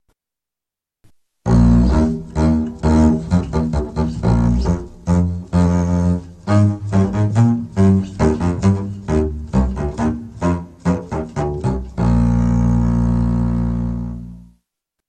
Contrabaixo
contrabajo.mp3